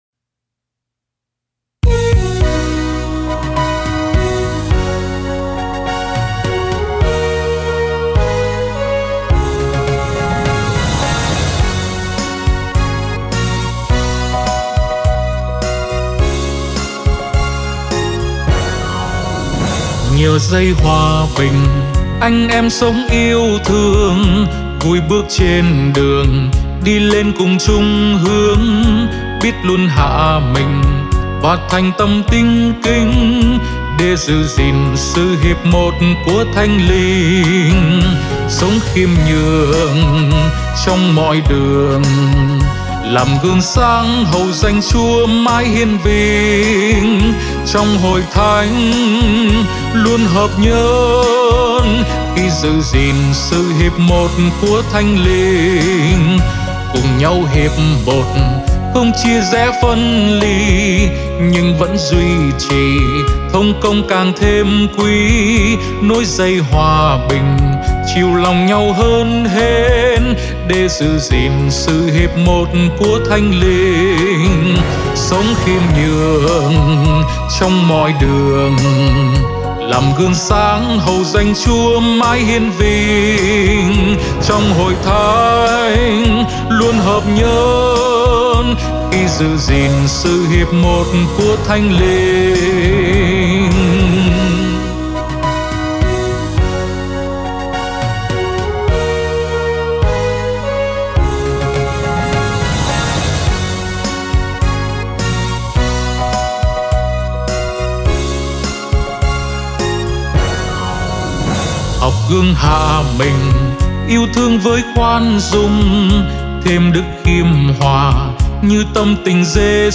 Nhạc Sáng Tác Mới